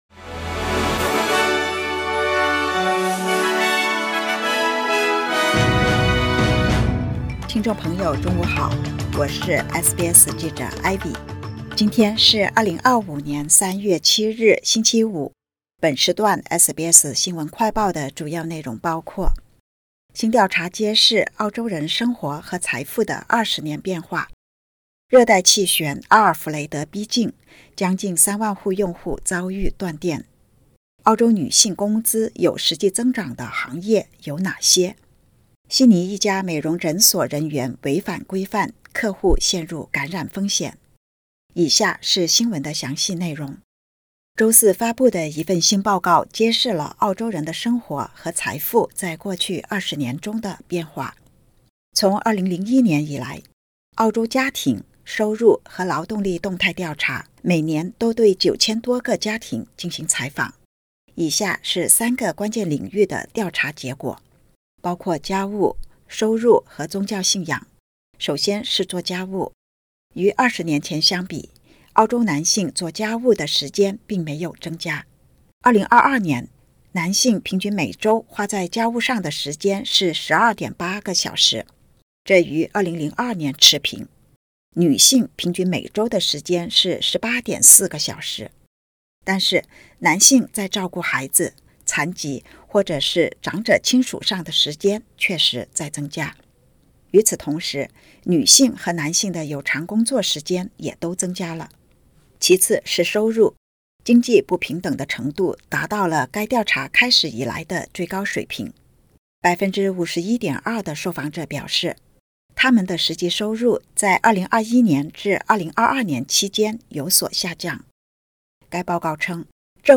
【SBS新闻快报】新报告揭澳洲人过去20年生活和财富的变化